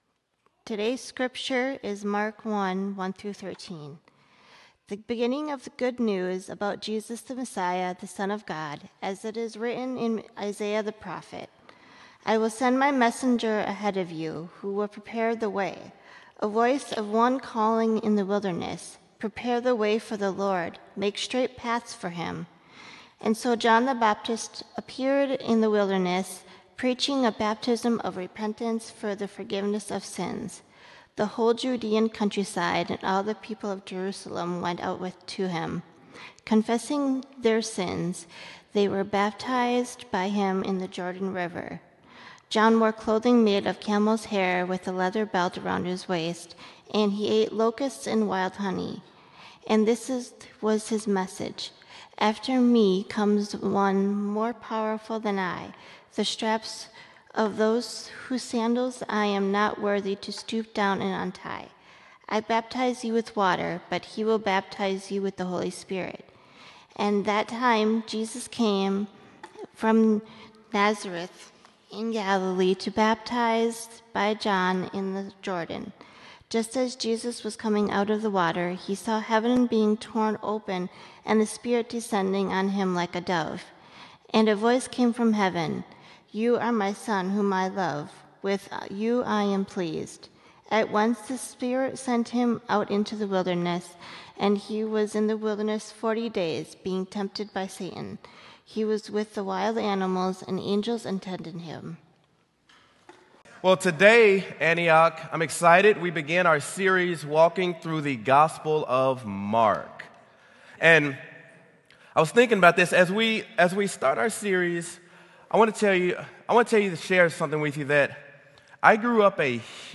Sermon: Mark: The Good News Has Come!
sermon-mark-the-good-news-has-come.m4a